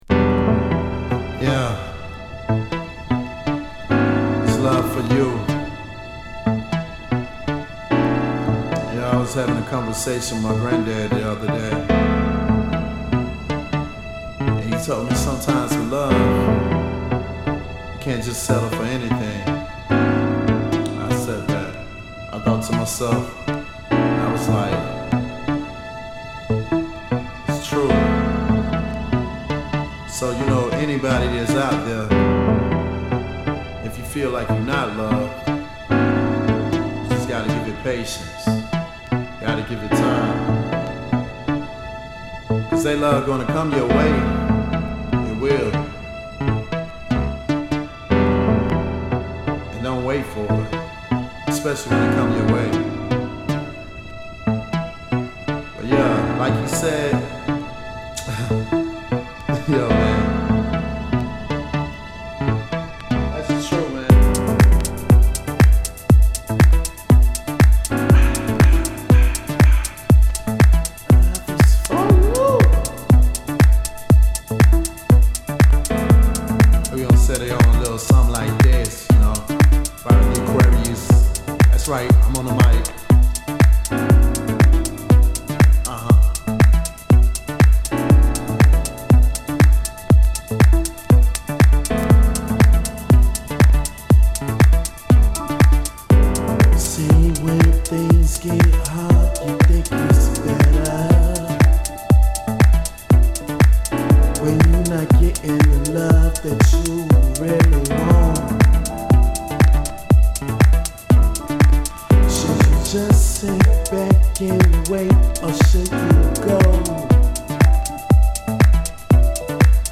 Saxophone, Vocoder